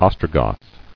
[Os·tro·goth]